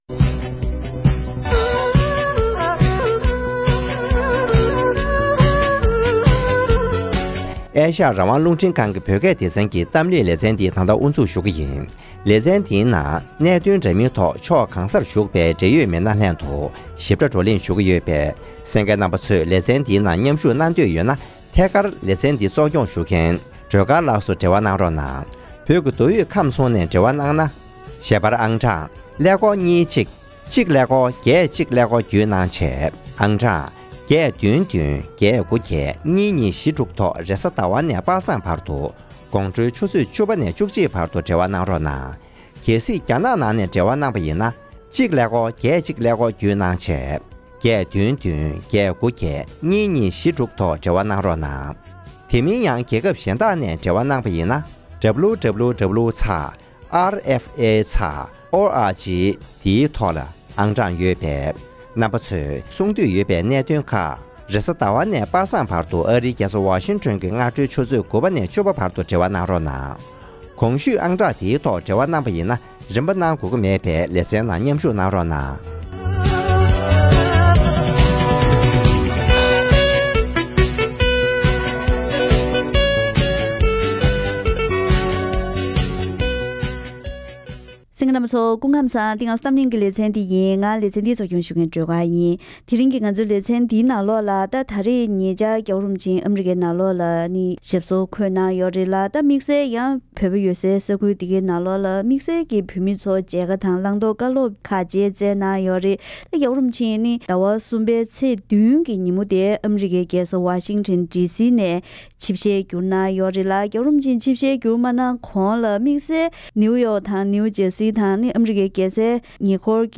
ཟླ་བ་འདིའི་ཚེས་༧ཉིན་བོད་མི་སྟོང་ཕྲག་ཙམ་ལ་གནང་བའི་བཀའ་སློབ་ཞིབ་ཕྲ་ངོ་སྤྲོད་ཞུས་པའི་ཐོག་ནས་མཇལ་ཁར་བཅར་མཁན་བོད་མི་ཁག་ཅིག་ལྷན་རིག་གཞུང་སྲུང་སྐྱོབ་སྐོར་གླེང་བ་ཞིག་གསན་རོགས་གནང་། །